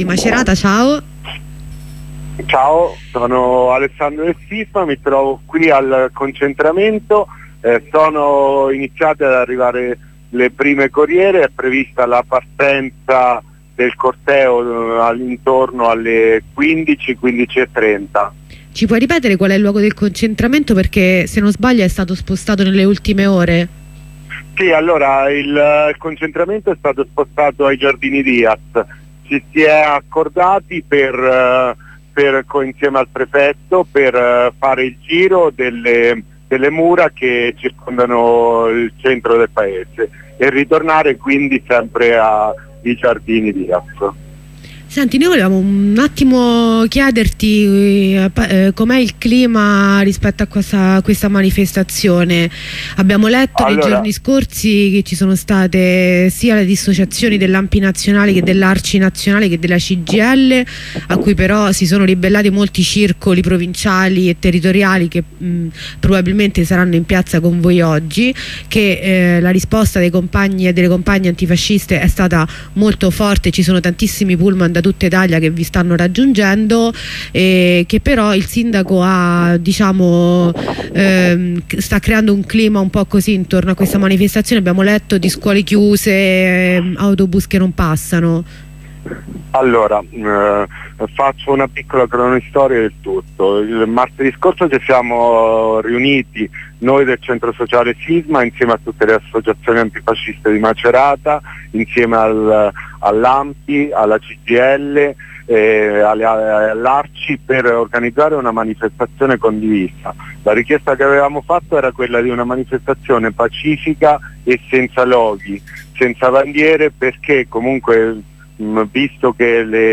Macerata: tutti gli interventi dal corteo